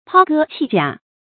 抛戈弃甲 pāo gē qì jiǎ
抛戈弃甲发音